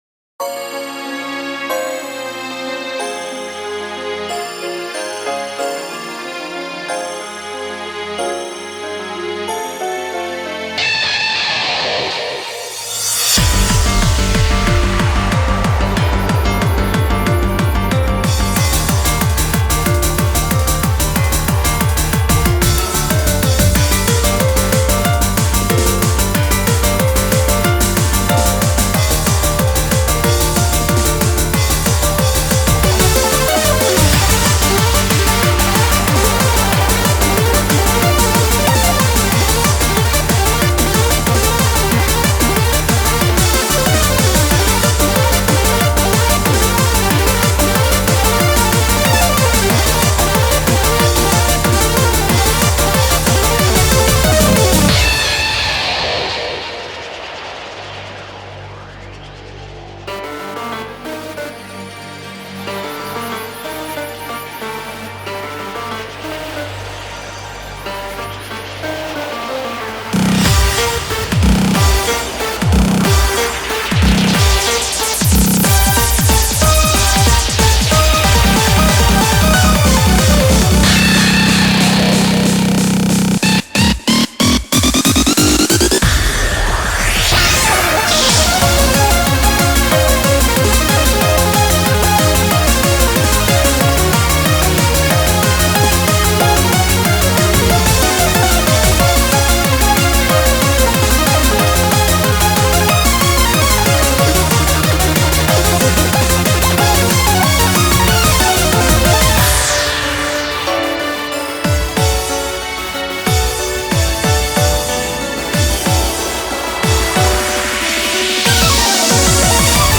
BPM185
Audio QualityPerfect (High Quality)